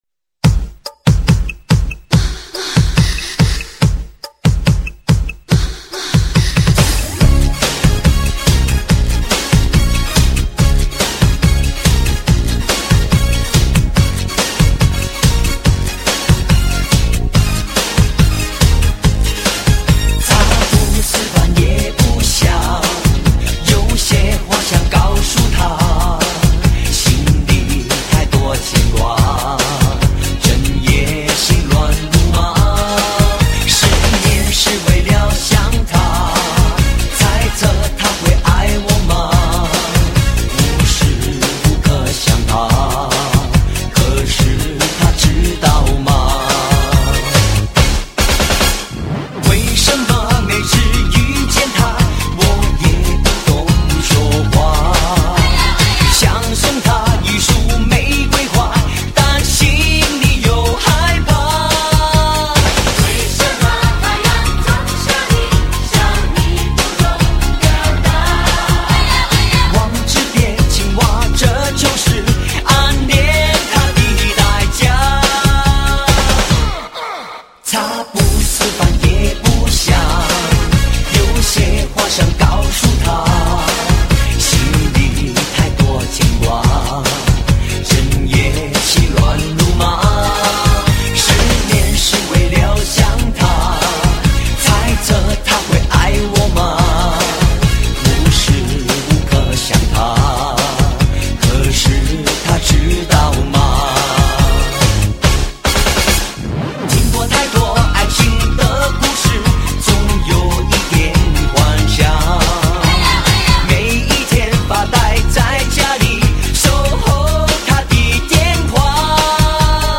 最感动至人华语老歌经典